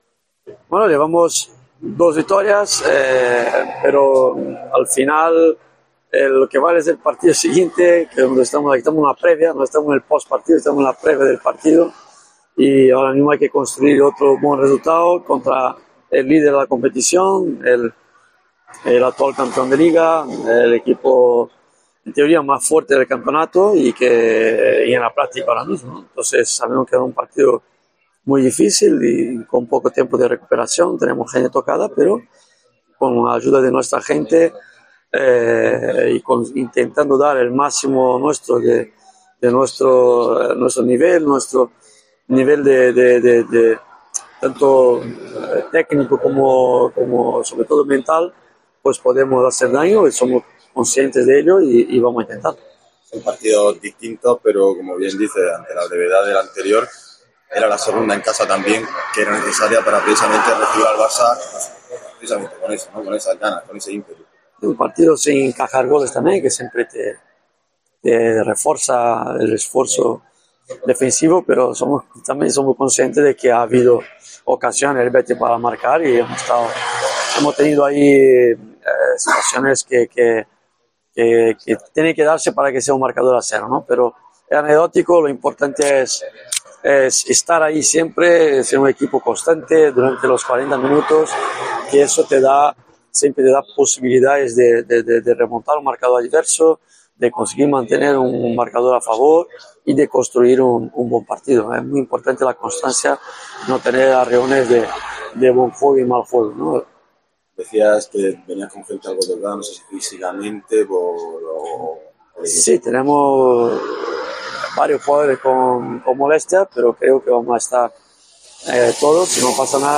Declaraciones de Duda antes del partido ante el Barcelona